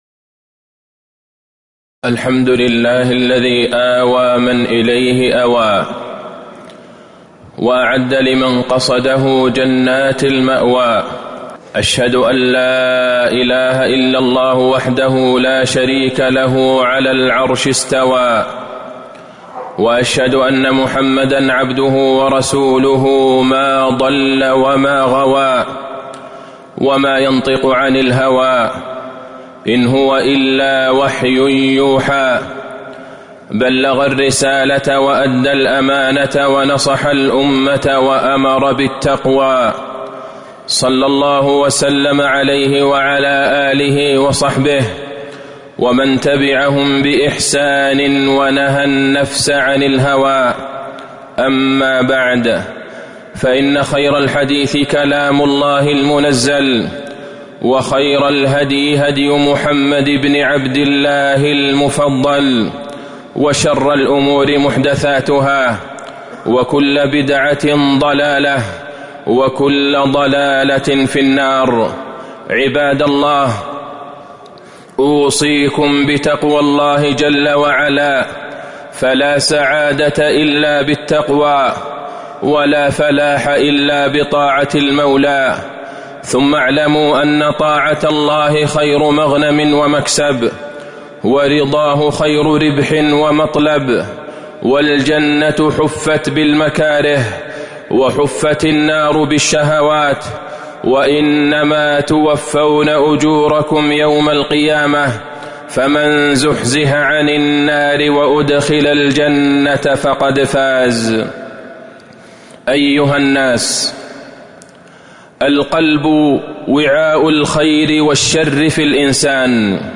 تاريخ النشر ١٢ شوال ١٤٤٣ هـ المكان: المسجد النبوي الشيخ: فضيلة الشيخ د. عبدالله بن عبدالرحمن البعيجان فضيلة الشيخ د. عبدالله بن عبدالرحمن البعيجان إصلاح القلوب